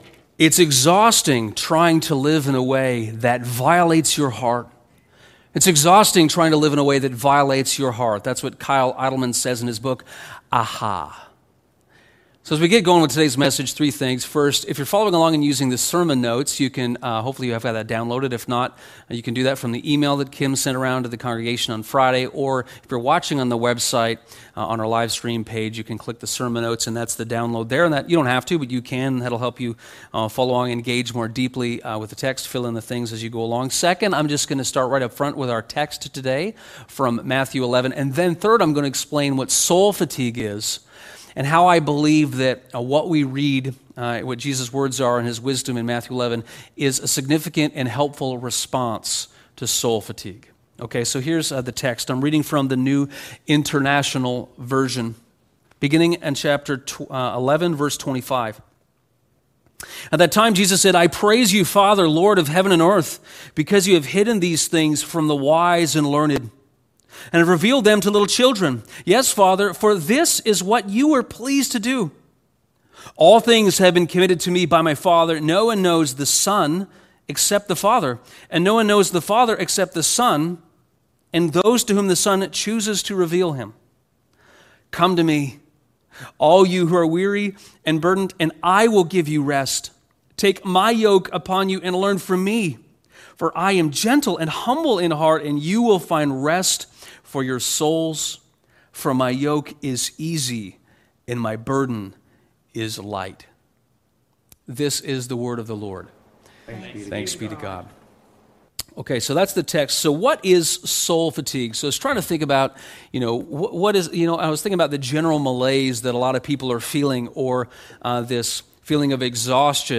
Soul fatigue is a deep, inner exhaustion many people are feeling in the midst of COVID-19. This sermon explores what is going on, and some relief from Jesus.